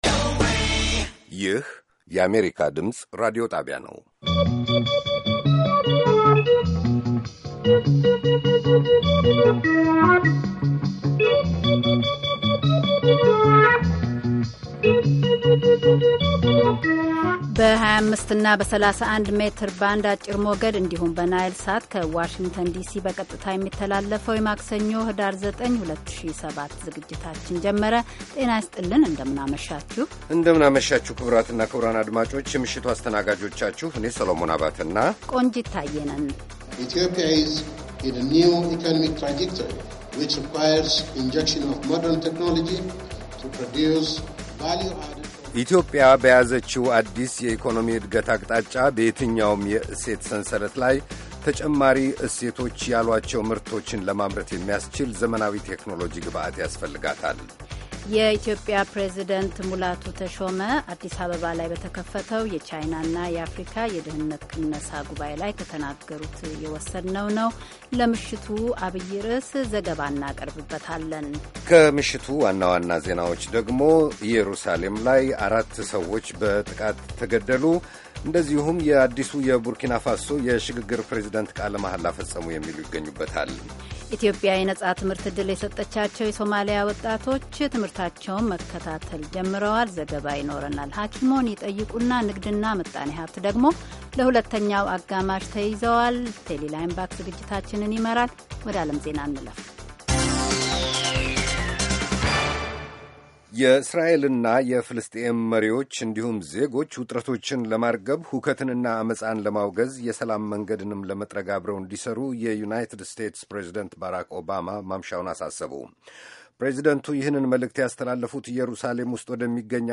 ከምሽቱ ሦስት ሰዓት የአማርኛ ዜና
ቪኦኤ በየዕለቱ ከምሽቱ 3 ሰዓት ጀምሮ በአማርኛ፣ በአጭር ሞገድ 22፣ 25 እና 31 ሜትር ባንድ ከሚያሠራጨው የ60 ደቂቃ ዜና፣ አበይት ዜናዎች ትንታኔና ሌሎችም ወቅታዊ መረጃዎችን የያዙ ፕሮግራሞች በተጨማሪ ከሰኞ እስከ ዐርብ ከምሽቱ 1 ሰዓት እስከ 1 ሰዓት ተኩል በአማርኛ የሚተላለፍ የግማሽ ሰዓት ሥርጭት በ1431 መካከለኛ ሞገድ ላይ አለው፡፡